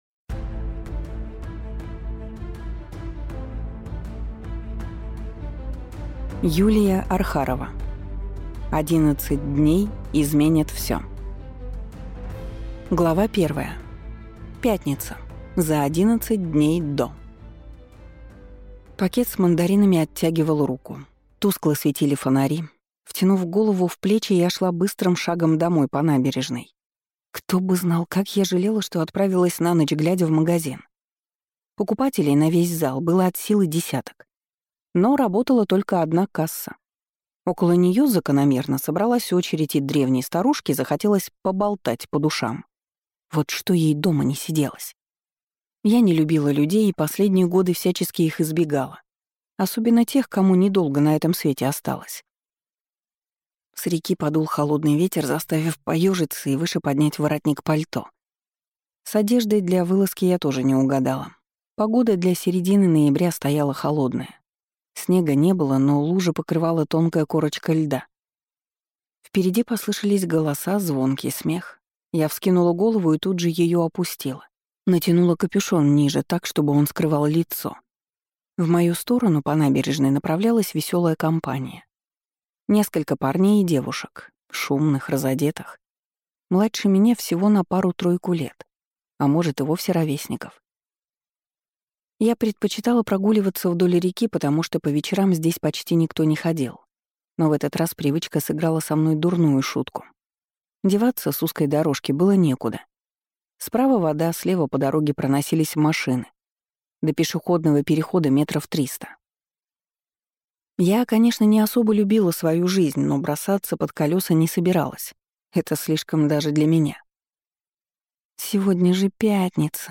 Аудиокнига 11 дней изменят всё | Библиотека аудиокниг